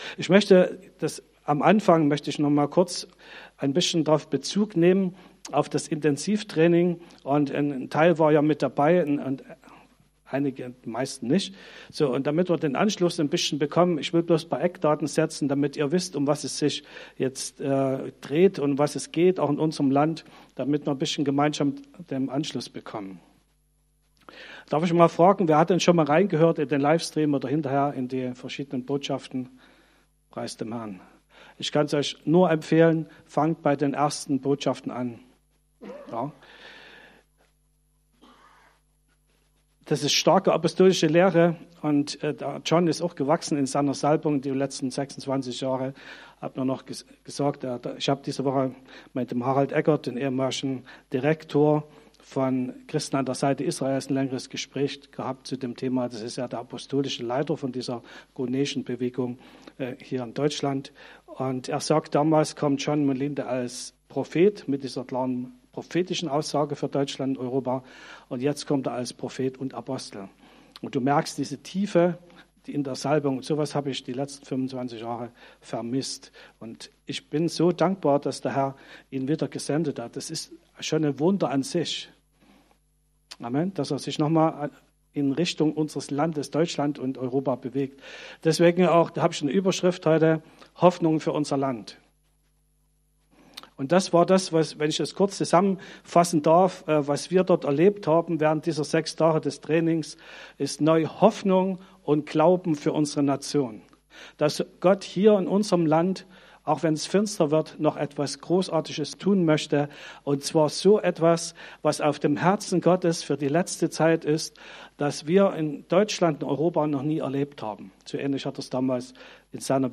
Hoffnung für unser Land / Predigten chronologisch sortiert / 12.